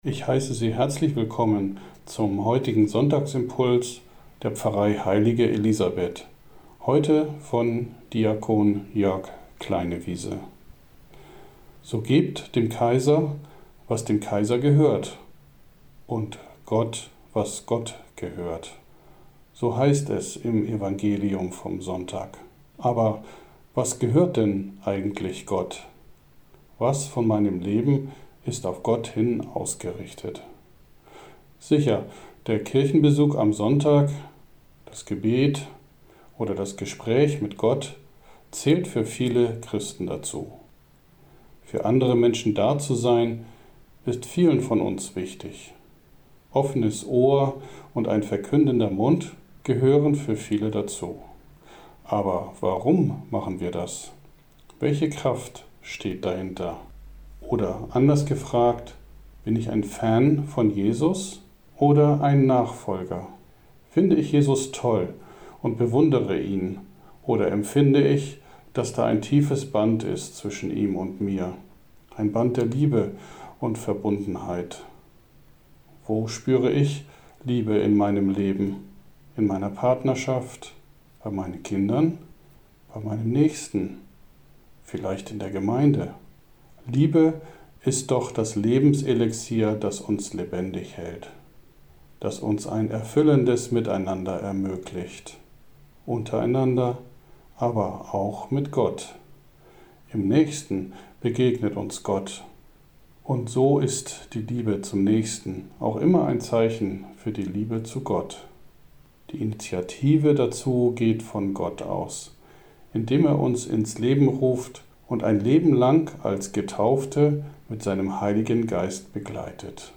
Fan von Jesus oder ein Nachfolger? – Sonntagsimpuls 18.10.2020